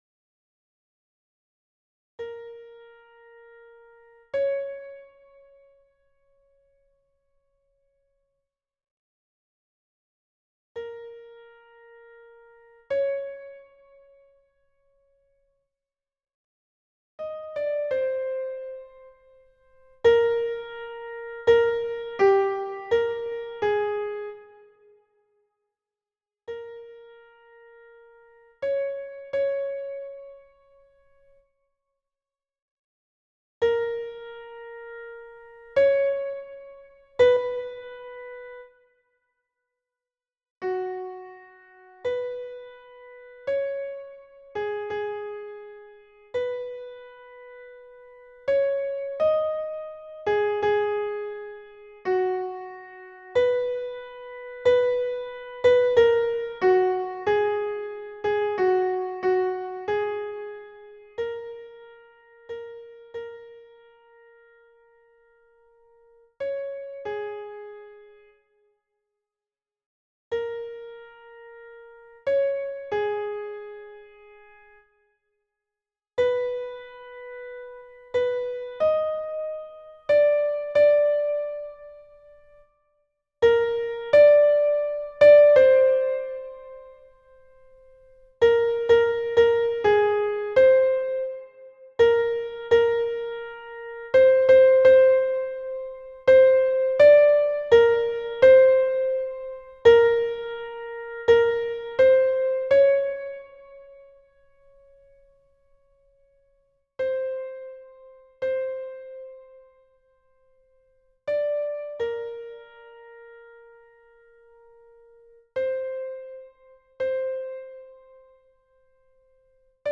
MP3 version piano
Tenor